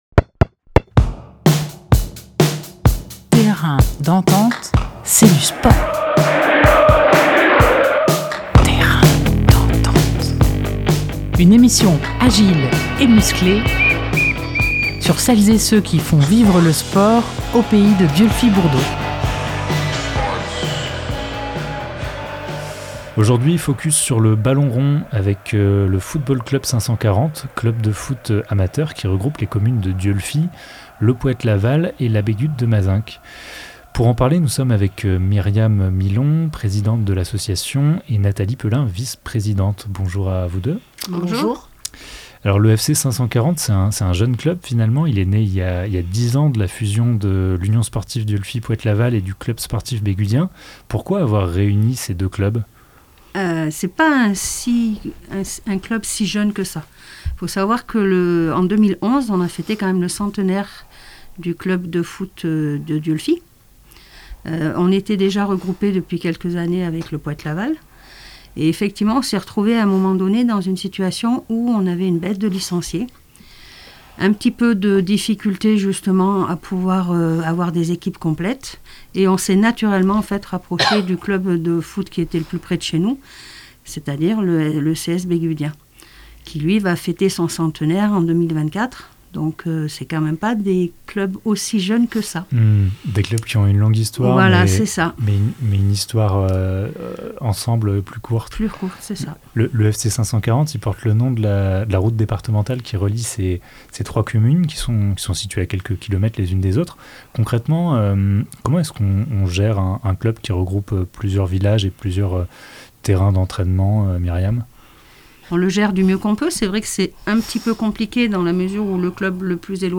23 janvier 2023 11:22 | actus locales, Interview, Terrain d'entente